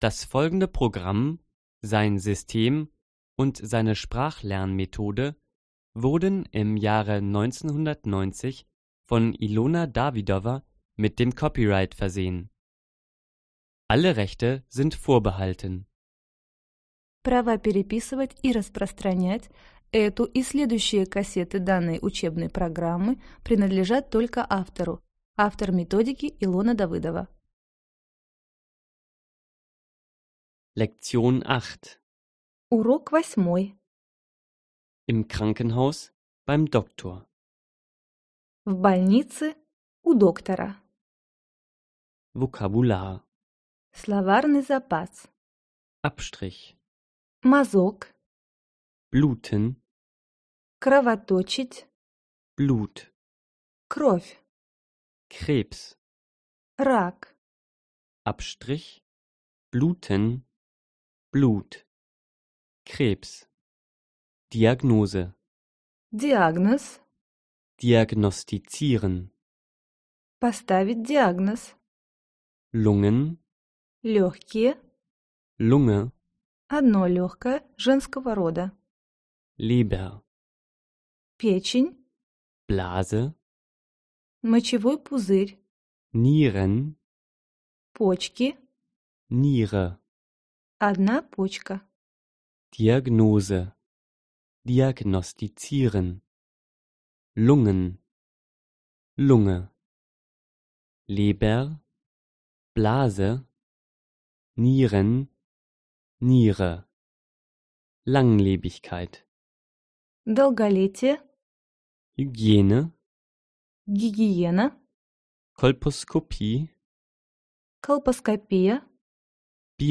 Аудиокнига Разговорно-бытовой немецкий язык. Диск 8 | Библиотека аудиокниг